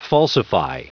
Prononciation audio / Fichier audio de FALSIFY en anglais
Prononciation du mot falsify en anglais (fichier audio)